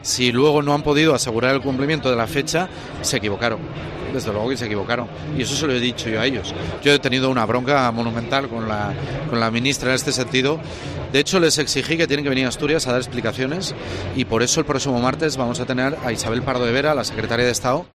En la entrevista que COPE Asturias le realizó con motivo del Día de Asturias en FITUR, Barbón reconoció que el ministerio se equivocó al anunciar que la Variante de Pajares se abriría en mayo.